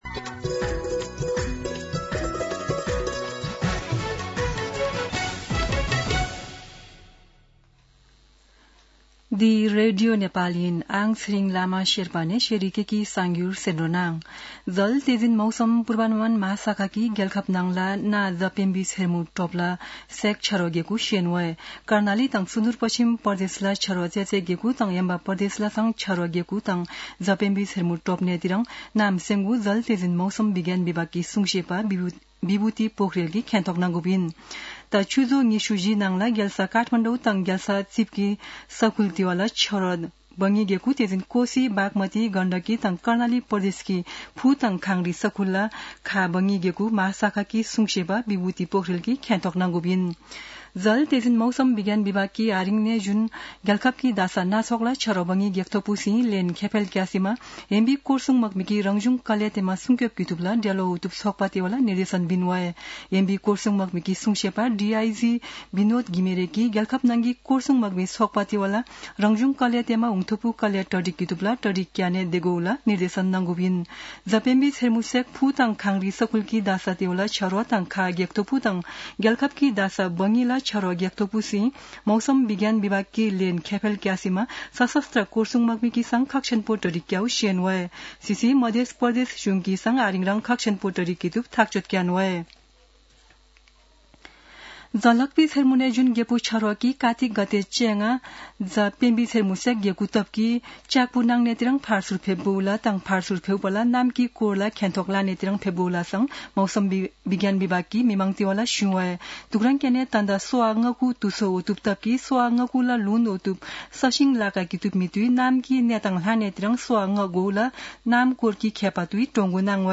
शेर्पा भाषाको समाचार : १३ कार्तिक , २०८२
Sherpa-News-7-13.mp3